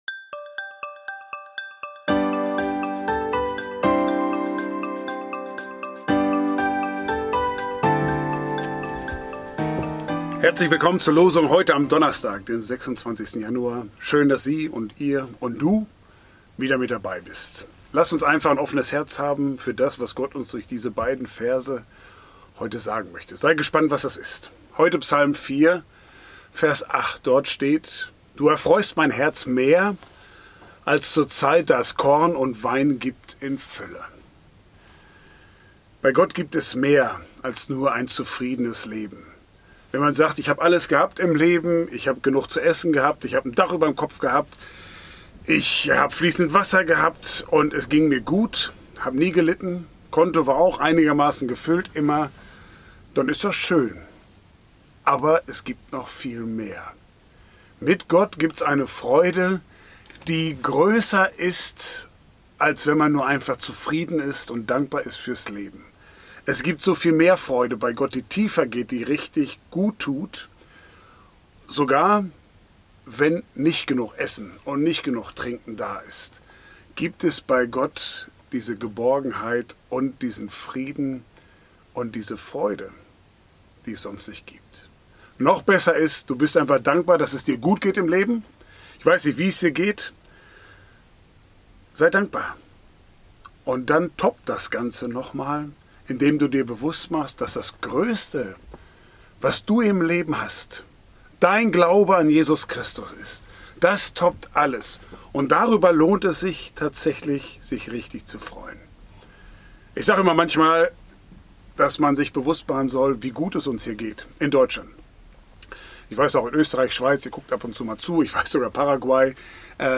Ein täglicher Impuls